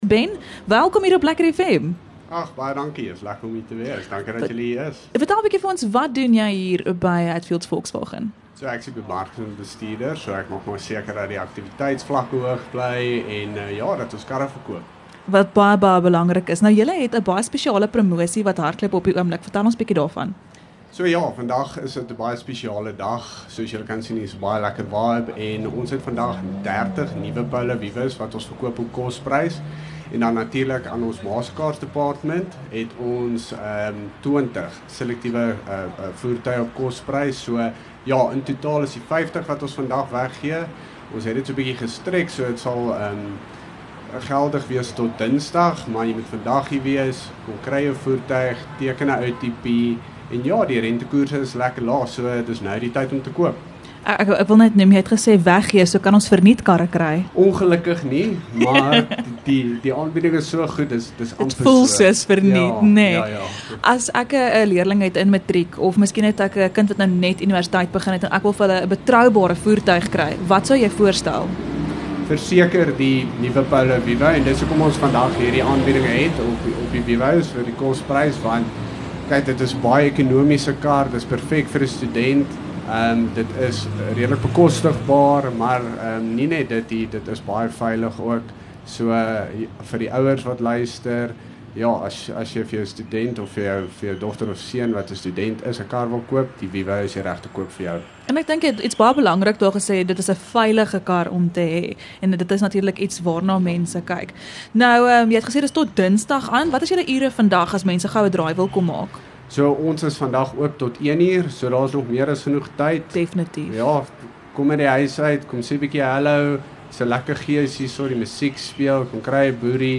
LEKKER FM | Onderhoude 15 Feb Hatfield Volkswagen Pretoria